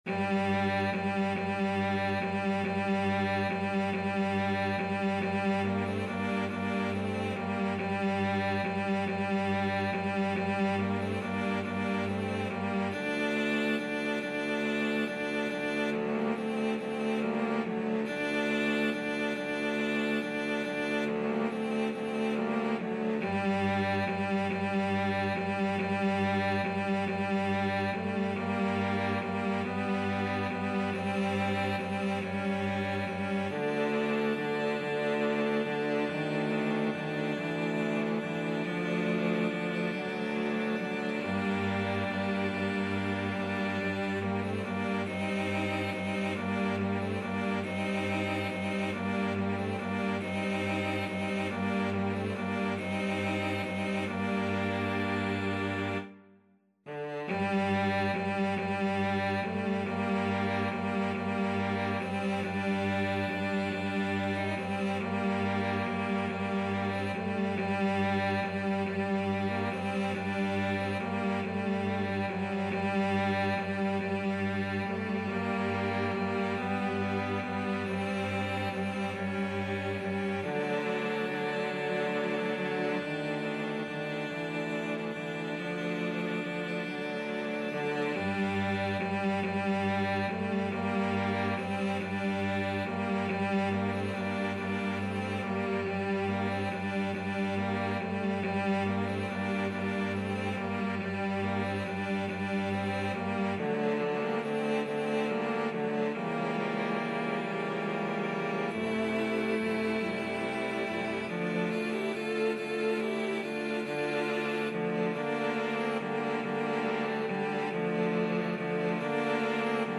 Free Sheet music for Cello Ensemble
G minor (Sounding Pitch) (View more G minor Music for Cello Ensemble )
~ =70 Andante
12/8 (View more 12/8 Music)
Classical (View more Classical Cello Ensemble Music)